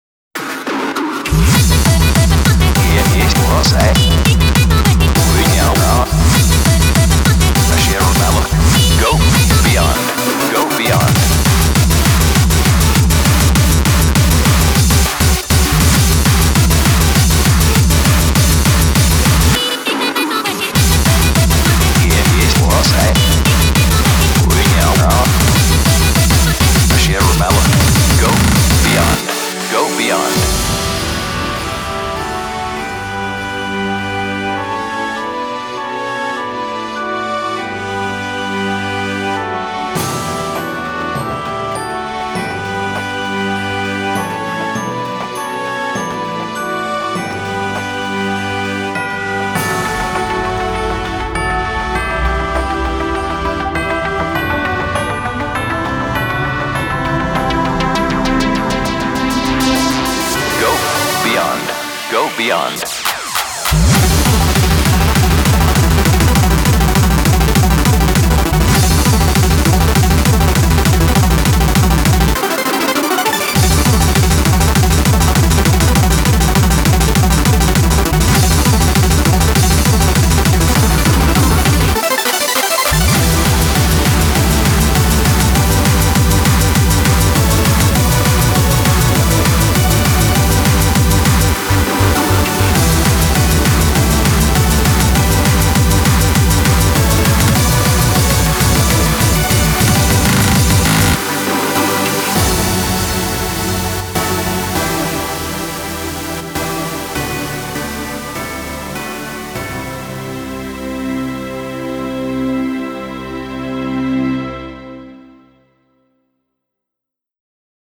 BPM200
Audio QualityLine Out